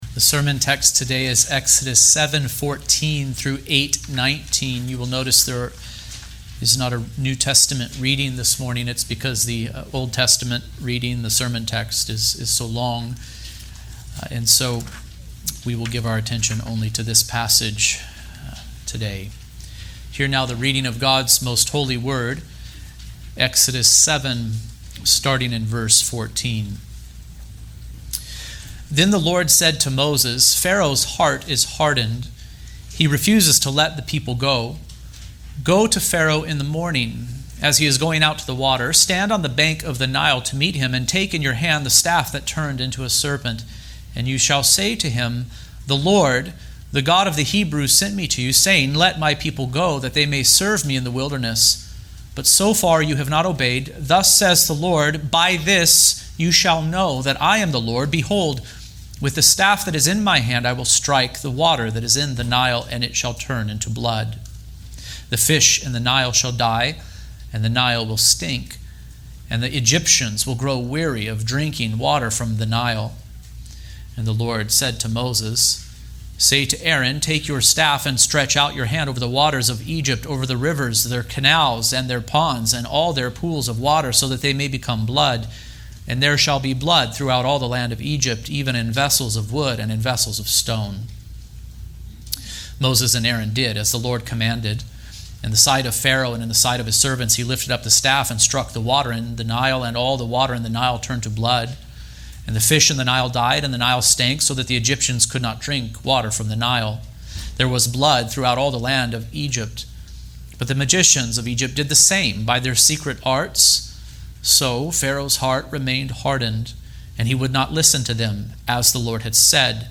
This Is The Finger Of God | SermonAudio Broadcaster is Live View the Live Stream Share this sermon Disabled by adblocker Copy URL Copied!